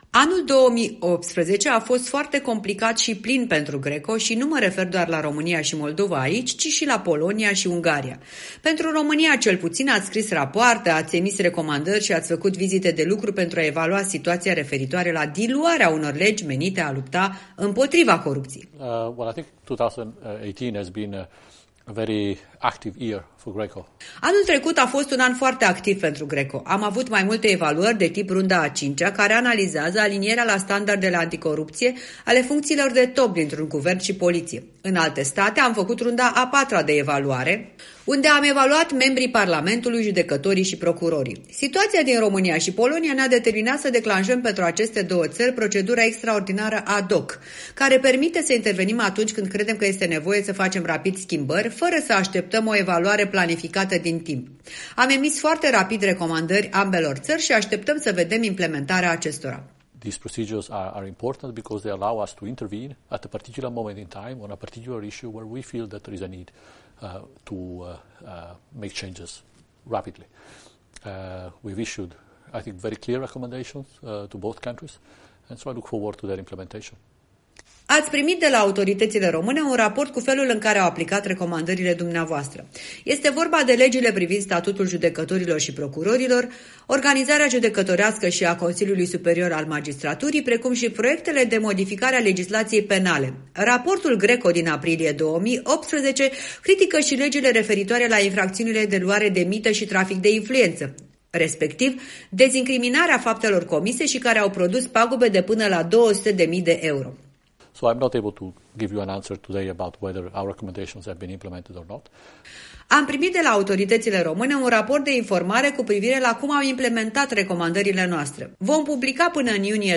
Interviu cu directorul executiv al GRECO, Gianluca Esposito